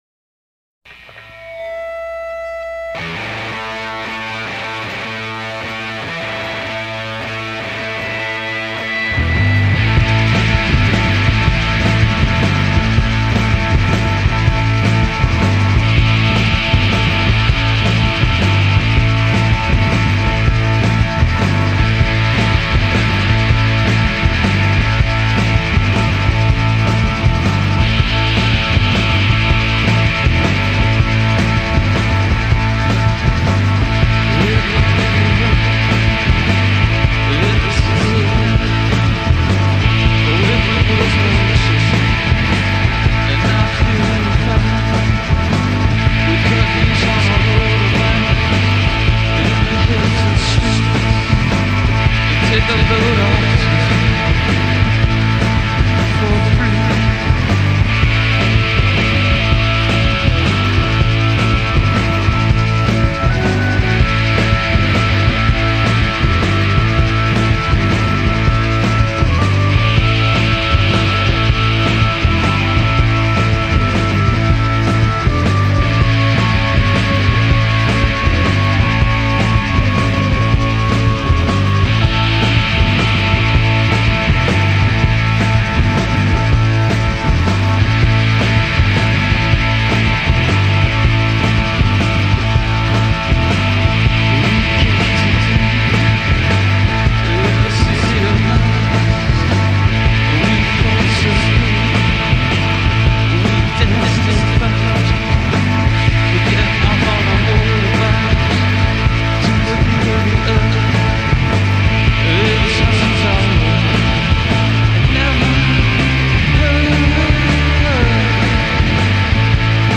neo-retro blues-infused psychedelic chug
Their deliciously fuzzed out guitars recall not just the 60s